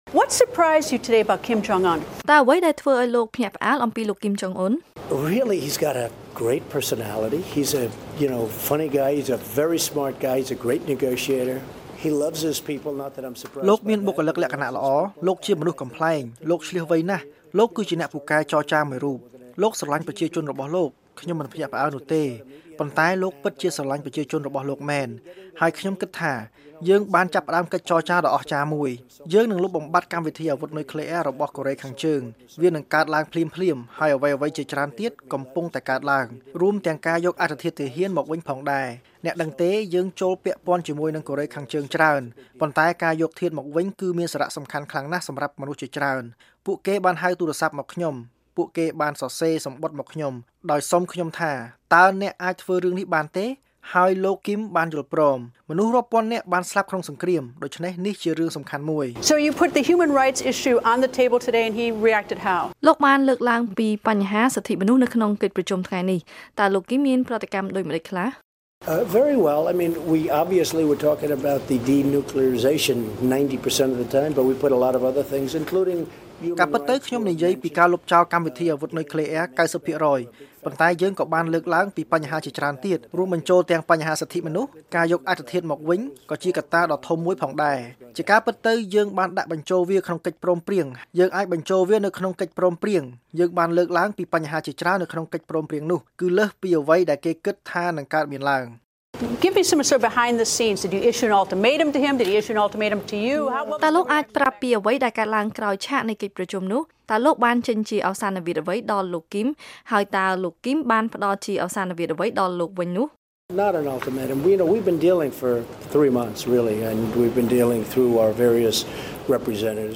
បទសម្ភាសន៍
កំណត់និពន្ធ៖ លោកប្រធានាធិបតី ដូណាល់ ត្រាំ ផ្តល់បទសម្ភាសន៍ផ្តាច់មុខដល់អ្នកស្រី Greta Van Susteren អ្នករាយការណ៍ព័ត៌មានជូន VOA បន្ទាប់ពីលោកបានបញ្ចប់កិច្ចប្រជុំកំពូលជាប្រវត្តិសាស្ត្រជាមួយនឹងមេដឹកនាំកូរ៉េខាងជើងលោក គីម ជុងអ៊ុន ដែលធ្វើឡើងនៅក្នុងប្រទេសសិង្ហបុរី អំពីអ្វីដែលមេដឹកនាំទាំងពីរបានពិភាក្សានិងអ្វីដែលរំពឹងថានឹងកើតឡើងបន្ទាប់។